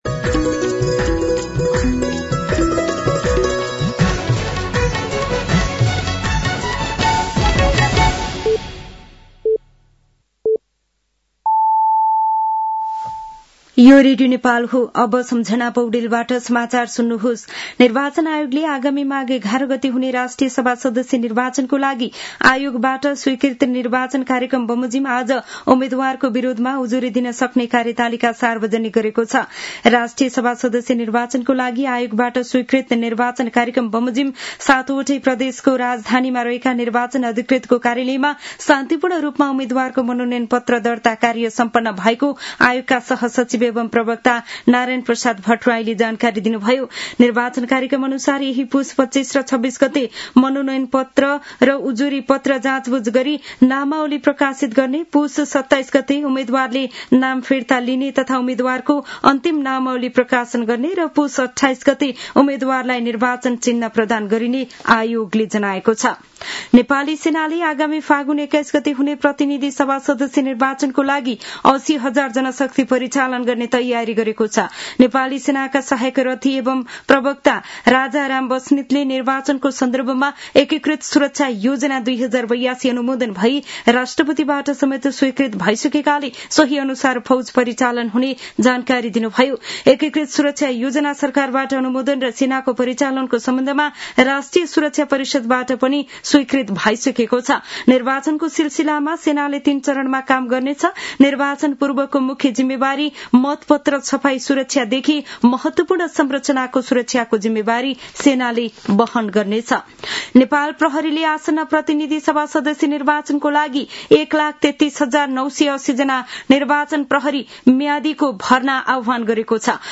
साँझ ५ बजेको नेपाली समाचार : २४ पुष , २०८२
5-pm-nepali-news-9-24.mp3